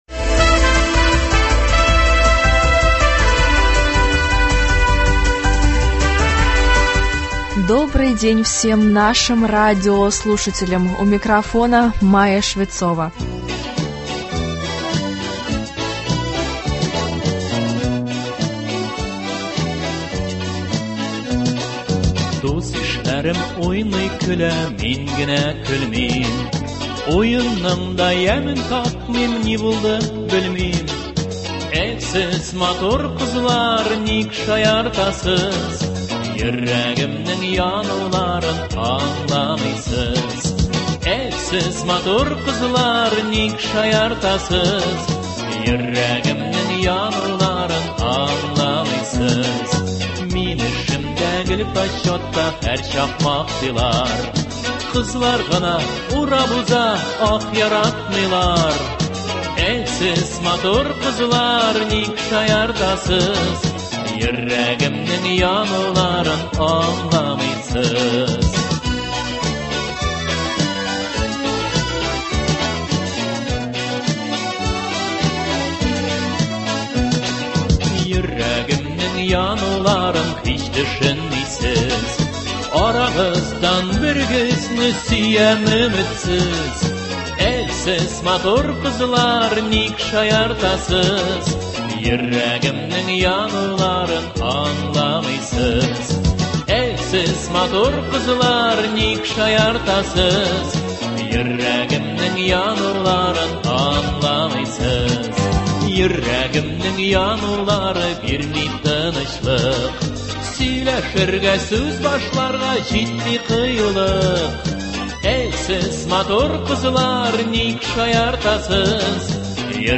Концерт к 8 марта. Поздравления певцов-артистов.